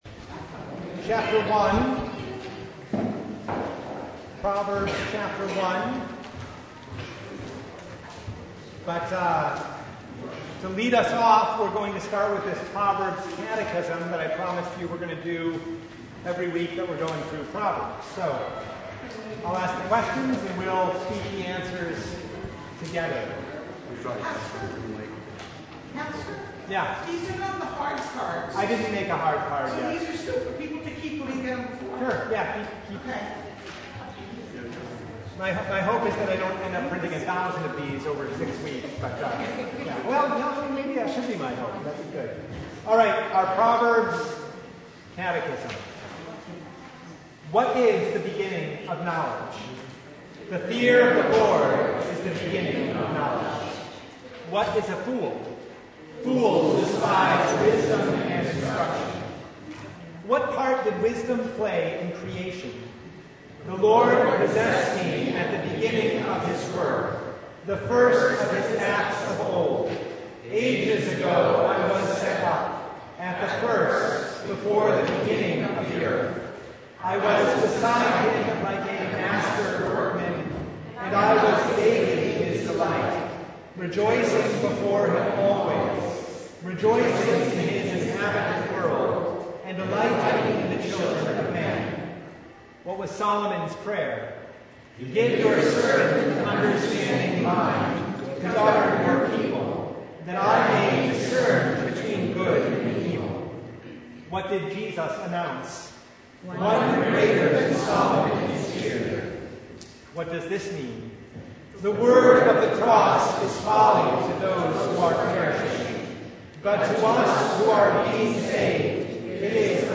Service Type: Bible Hour